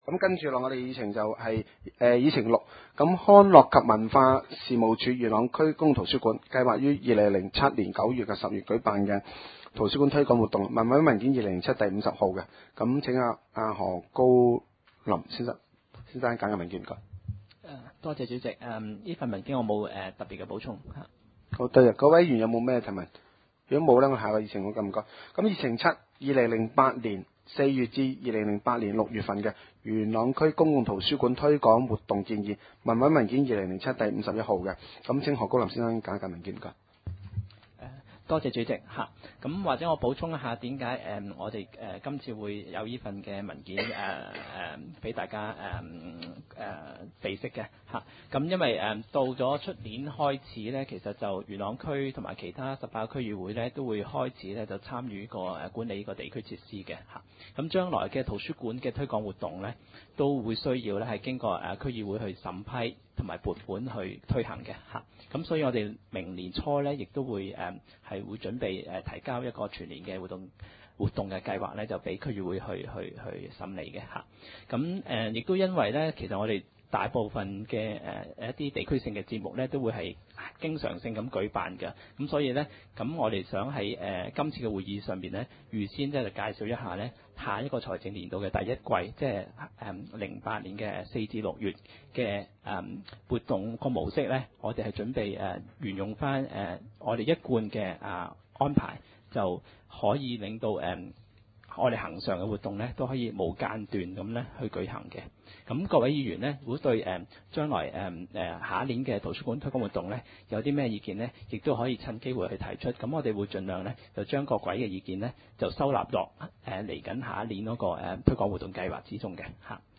點︰元朗區議會會議廳